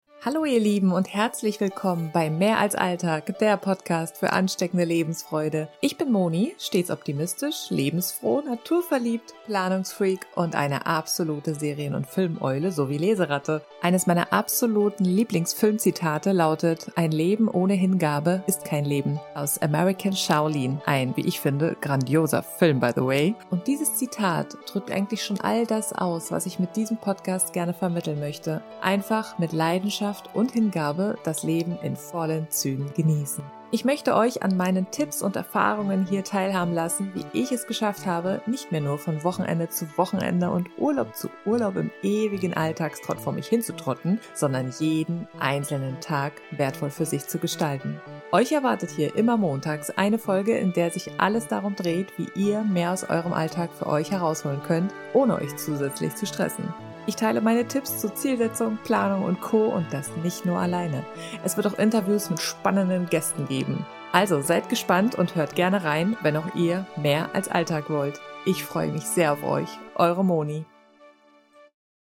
Hintergrundmusik des Trailers: